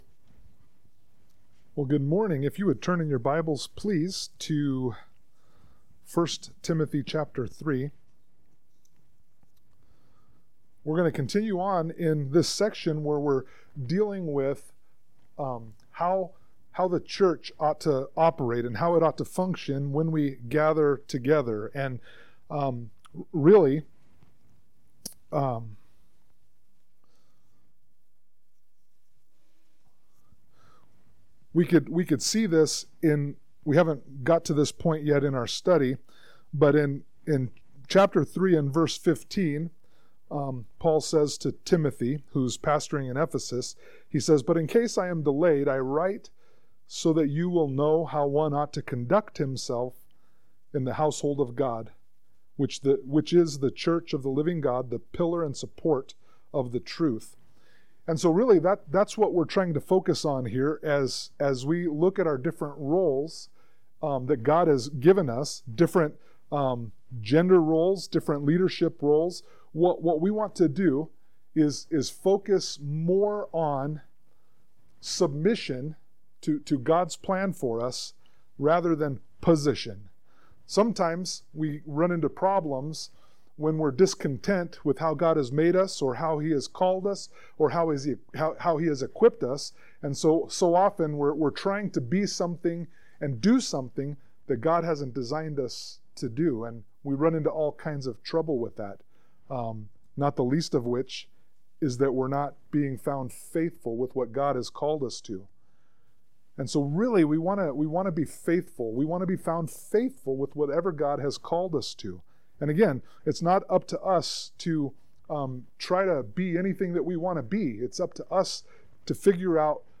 Sermon-8_31_25.mp3